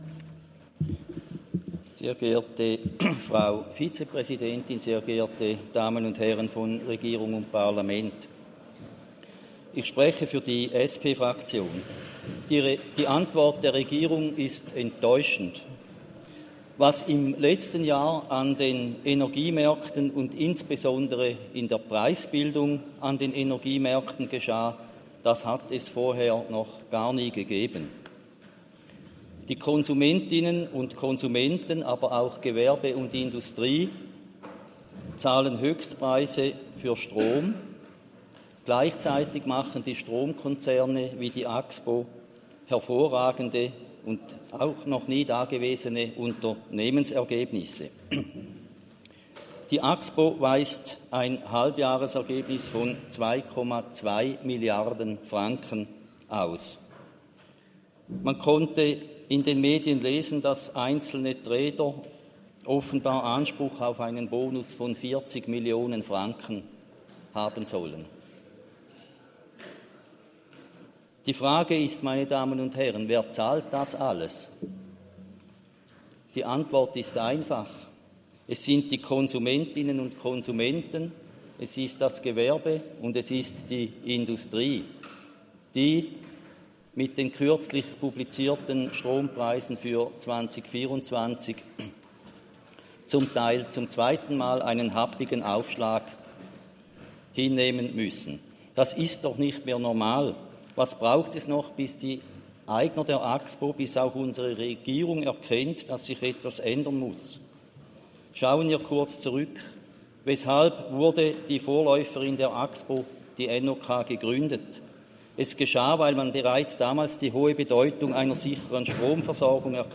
Geschäft des Kantonsrates St.Gallen: Politische Kontrolle über die Axpo zurückgewinnen
Jans-St.Gallen (im Namen der SP-Fraktion): Die Interpellantin ist mit der Antwort der Regierung nicht zufrieden.